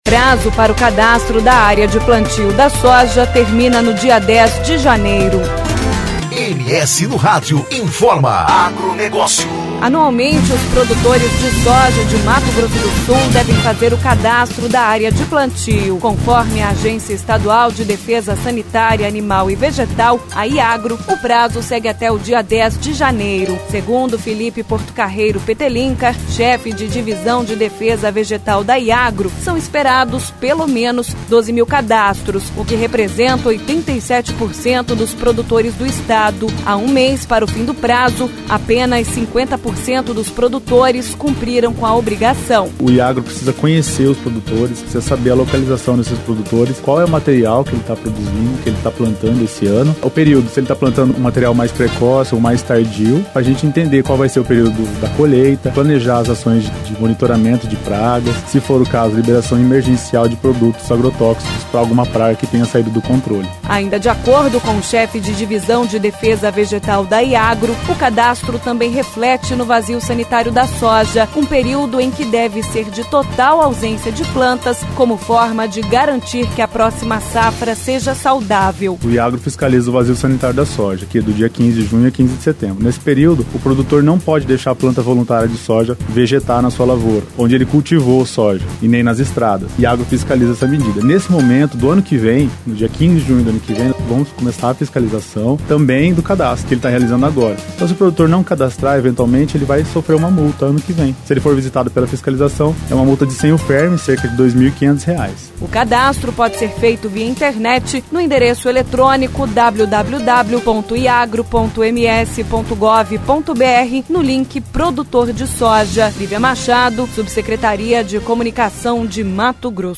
Boletim Informativo: cadastro de área de plantio da soja